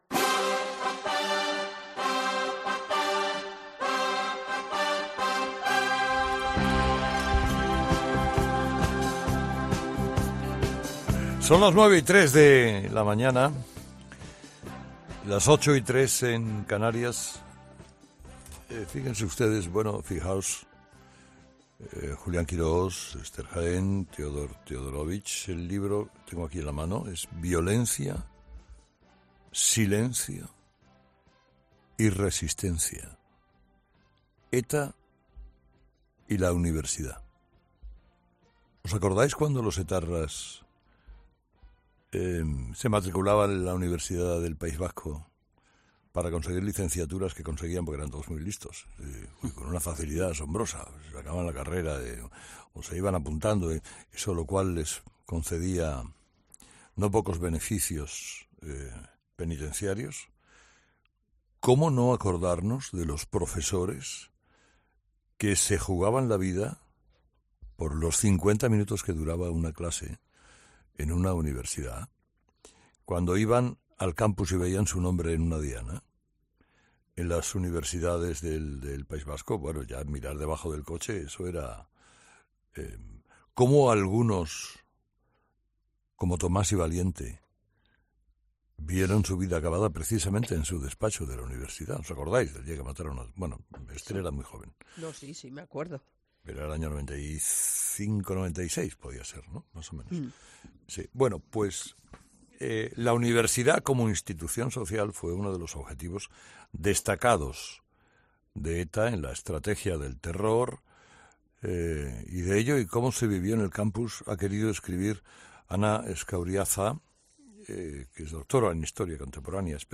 Este miércoles ha estado en 'Herrera en COPE' presentando el libro.